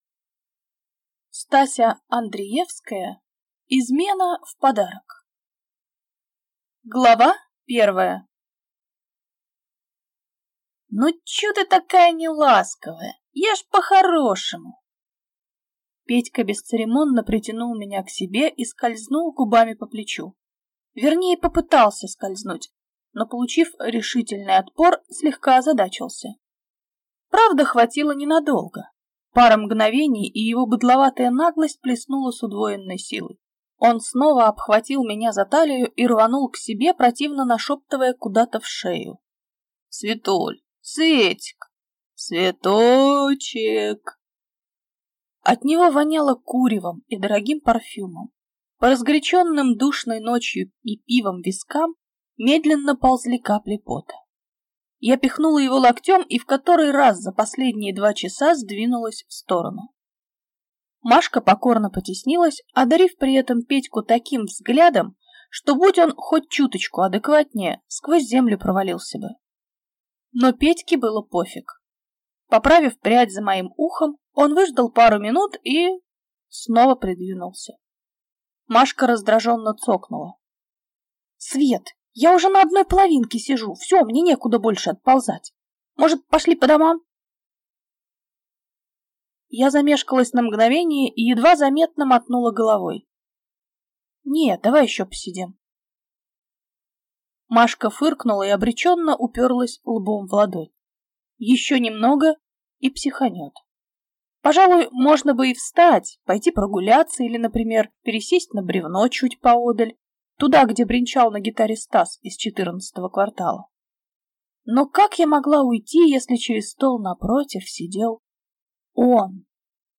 Аудиокнига Измена в подарок | Библиотека аудиокниг